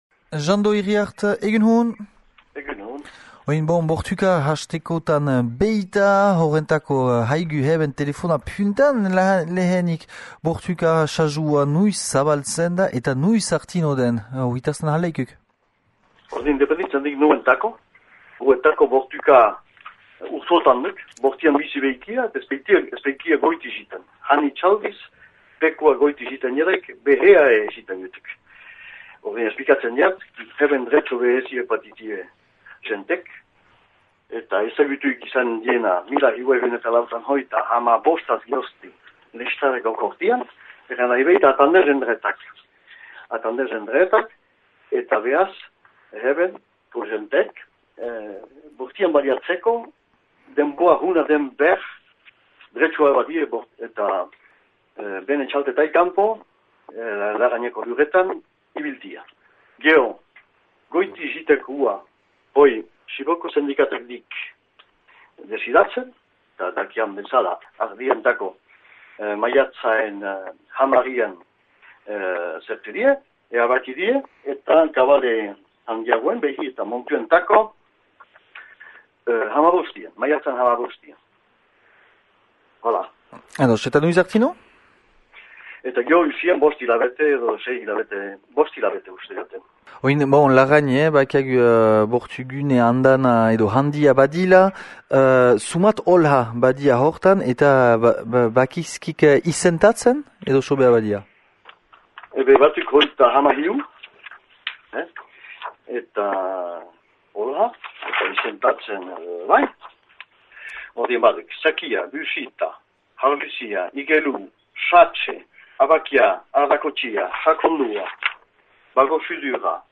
Bortüala joaiteko sasua hasirik beita artzainentako, zer dütüen arrenkürak, xiberoko sindikatarekin harremana edo ere sühaketaz mintzatü gira Jean Do Iriart Larraineko aüzapezarekin :